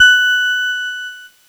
Cheese Note 24-F#4.wav